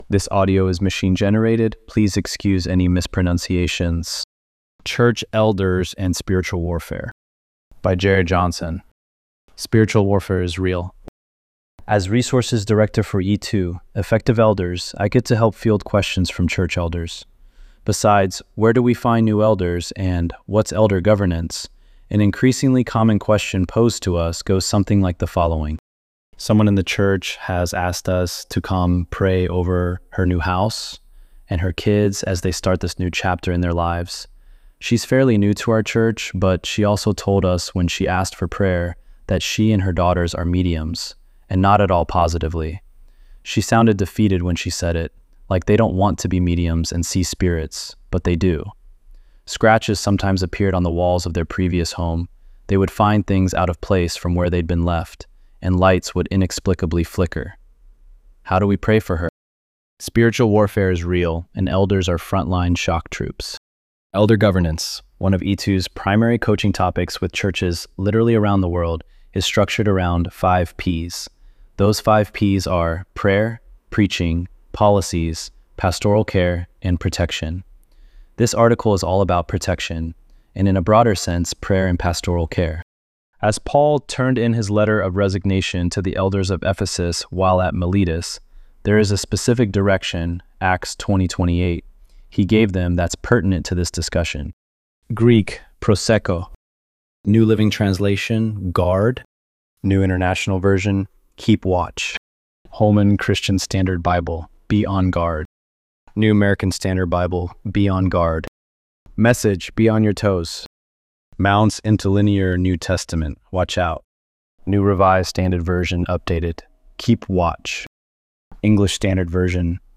ElevenLabs_4_22.mp3